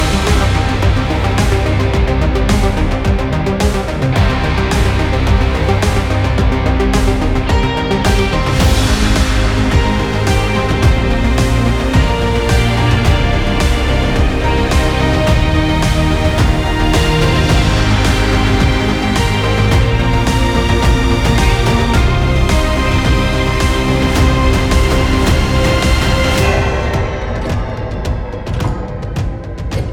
Жанр: Нью-эйдж